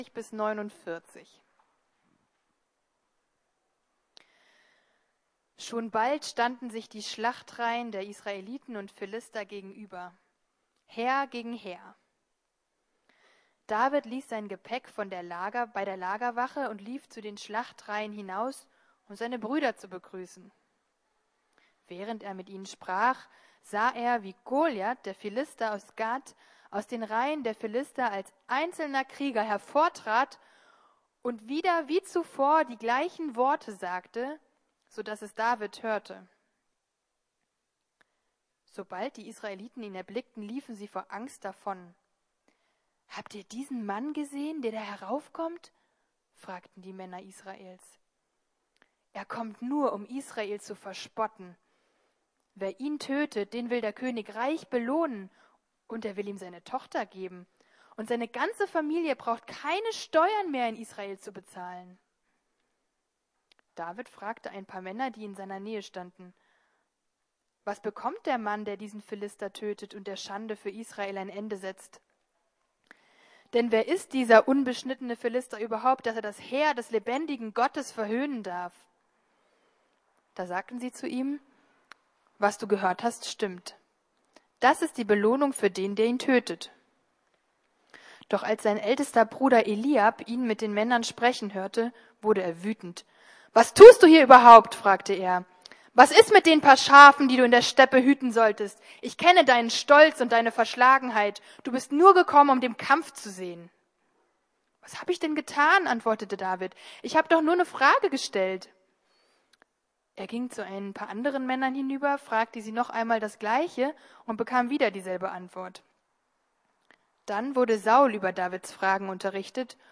Wahrer Glaube, wahrer König ~ Predigten der LUKAS GEMEINDE Podcast